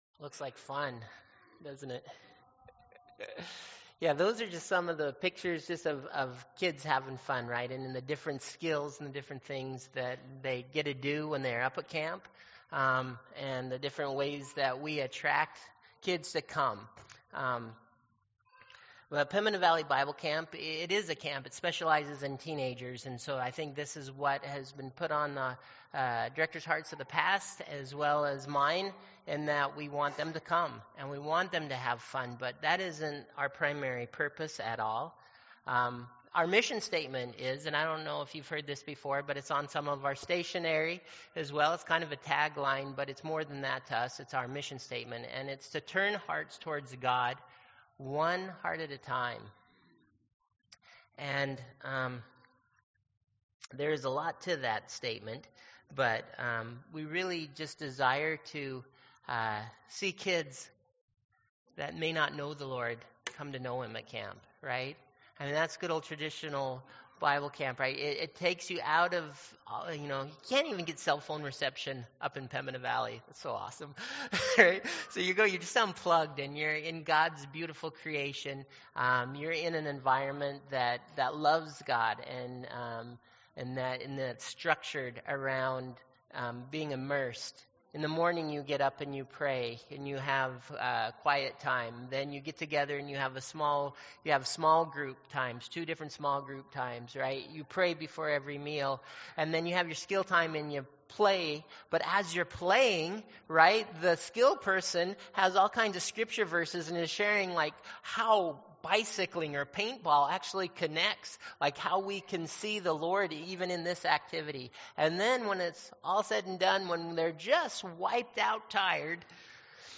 May 31, 2015 – Sermon